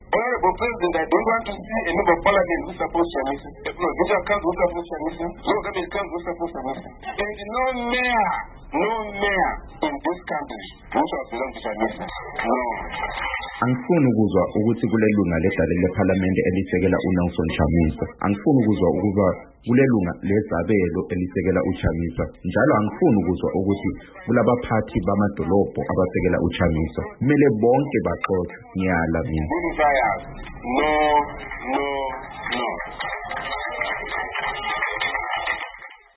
UMorgan Komichi Ekhuluma Emhlanganweni Webandla leMDC-T NgeSonto